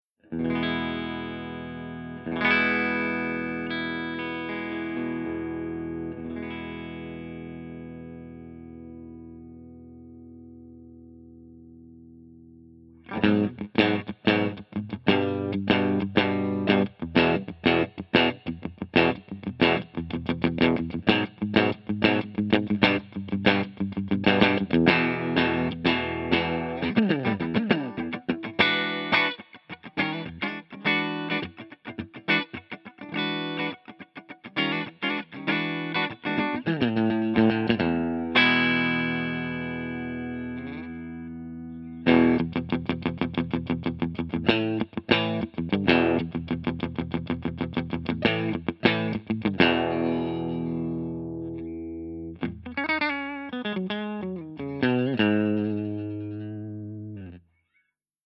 077_MARSHALLJTM60_CLEANBRIGHT_REVERB_SC.mp3